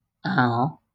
.wav Audio pronunciation file from the Lingua Libre project.
Transcription InfoField ɑo²